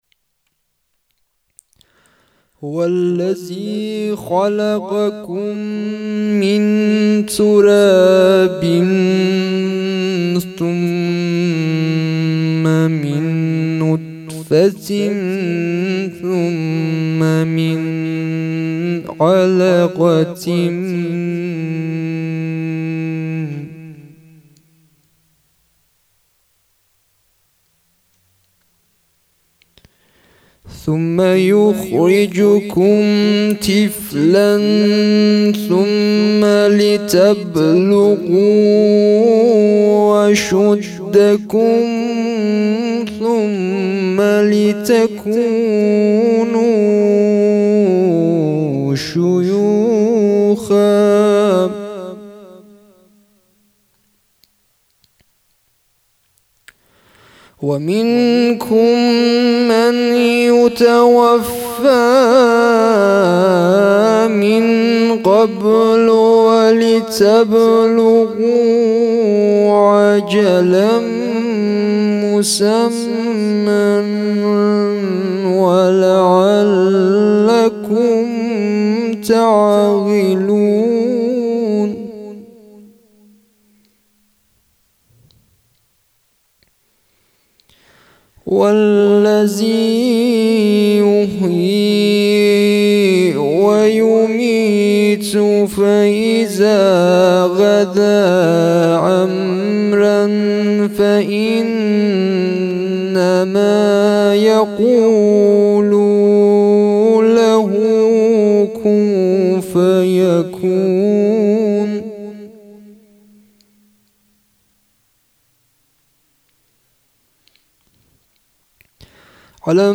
خیمه گاه - هیئت ثارالله شهرستان رودسر - quran